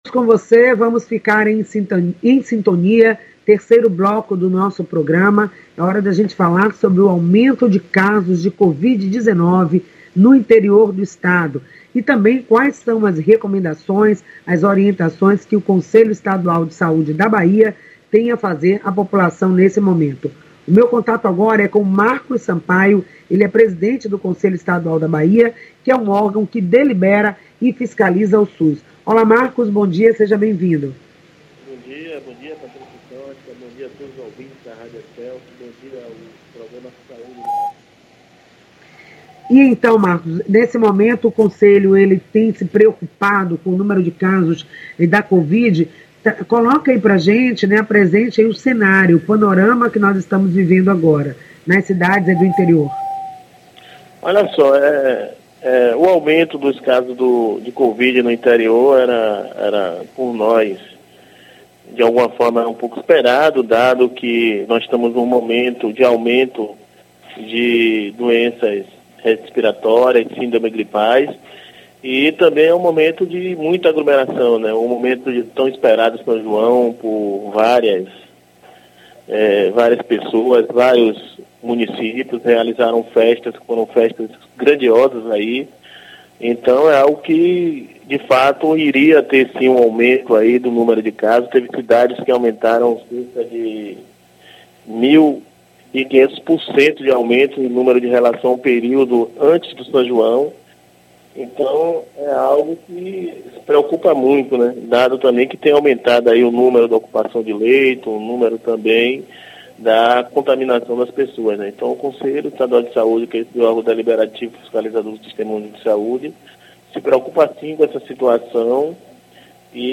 O programa acontece de segunda à sexta, das 9 às 9:55h, pela Rádio Excelsior AM 840.